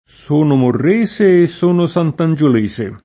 santangiolese [SantanJol%Se] etn. (di Sant’Angelo) — così per quasi tutti i paesi di questo nome (pur con -ge-, non -gio-, nel top. uffic.): sono morrese e sono santangiolese [